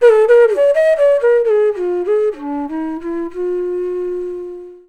FLUTE-B06 -R.wav